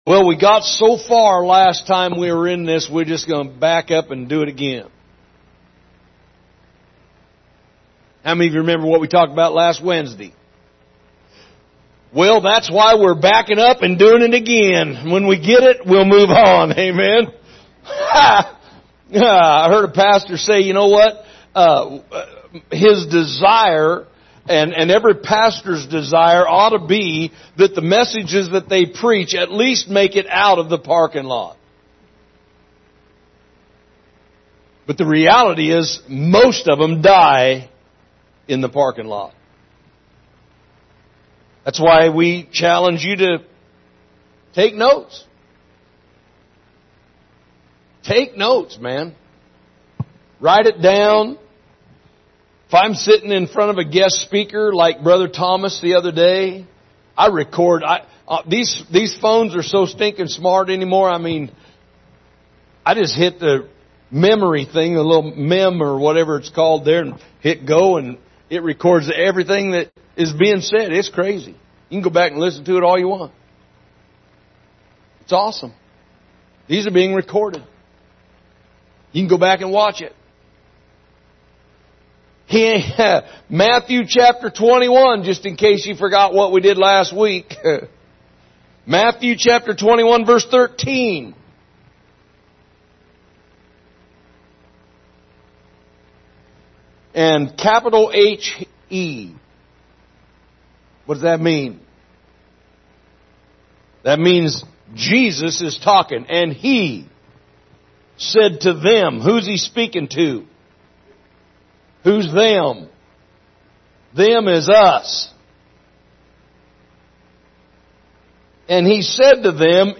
Category: Teachings